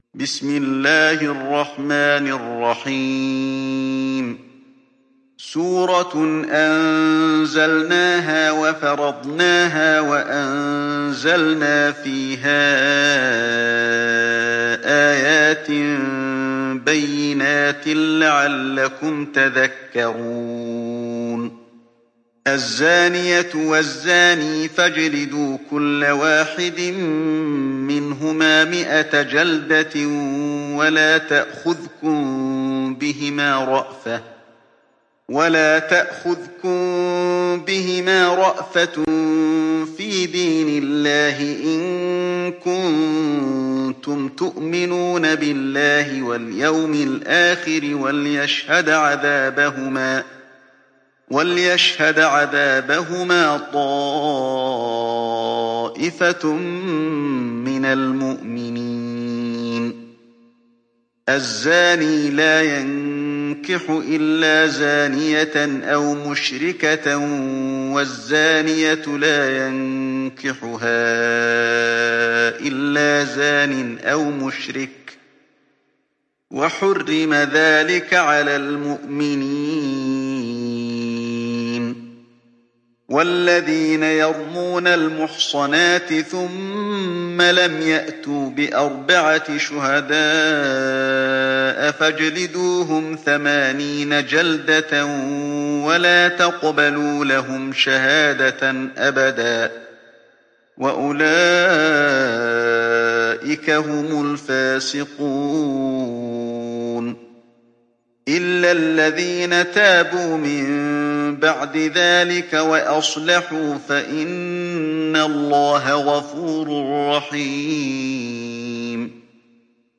تحميل سورة النور mp3 بصوت علي الحذيفي برواية حفص عن عاصم, تحميل استماع القرآن الكريم على الجوال mp3 كاملا بروابط مباشرة وسريعة